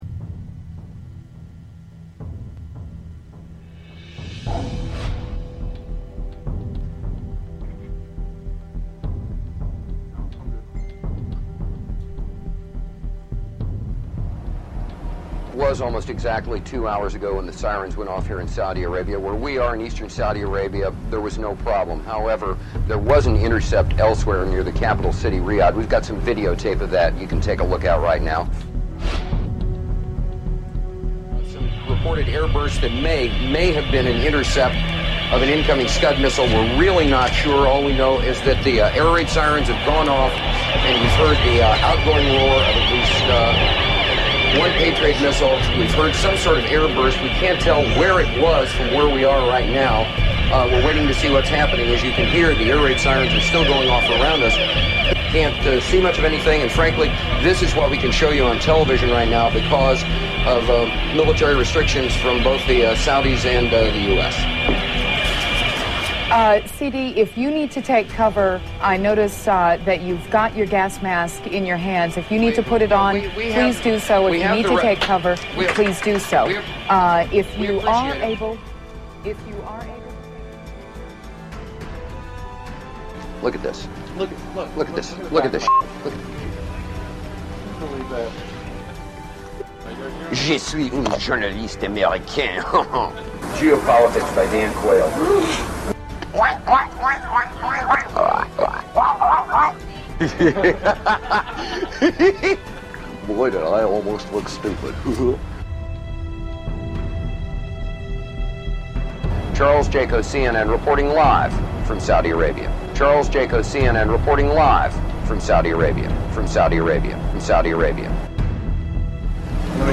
Talk Show Episode, Audio Podcast, Sons of Liberty Radio and Americans Must STOP The Bleeding!